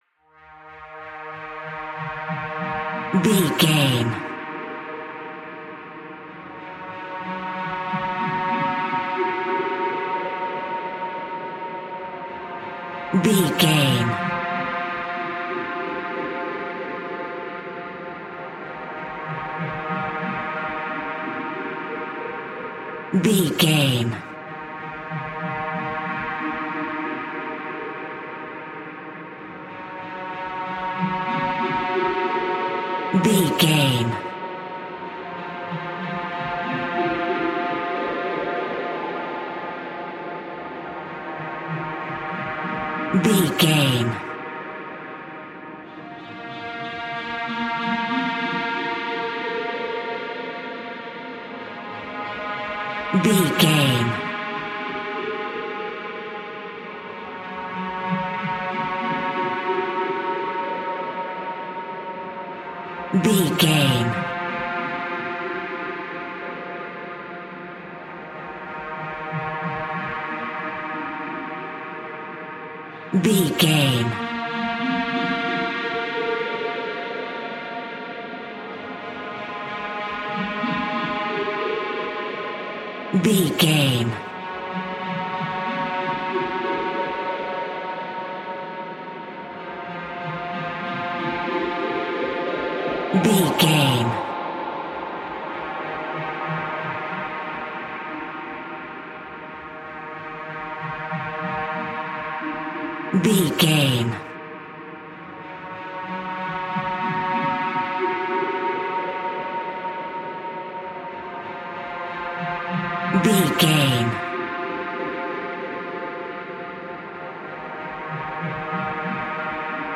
In-crescendo
Thriller
Aeolian/Minor
tension
ominous
eerie
instrumentals
horror music
Horror Synths